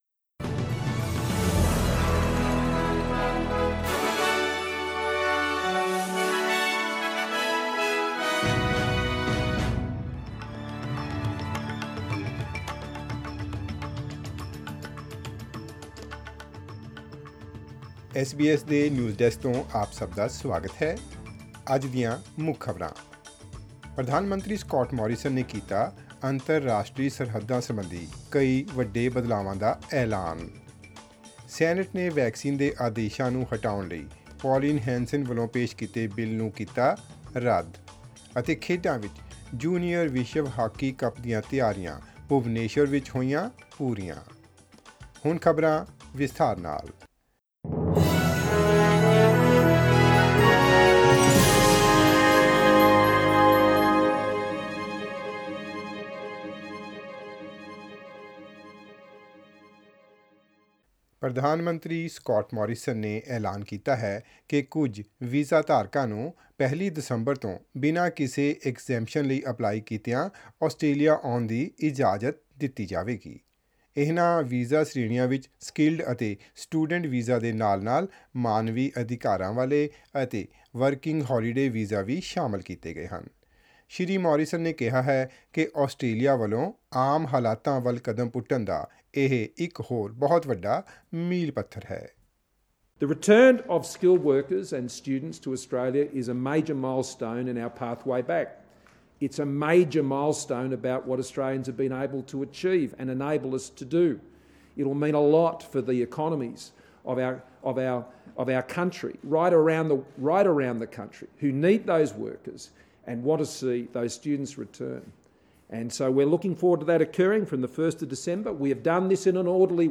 Listen to SBS Radio news in Punjabi.